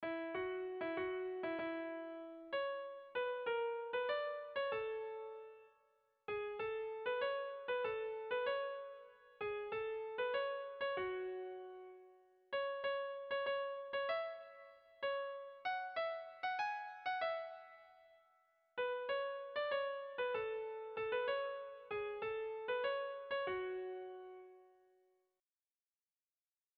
Erromantzea
Zortziko txikia (hg) / Lau puntuko txikia (ip)
AAB1DB2